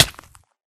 damage / hurtflesh1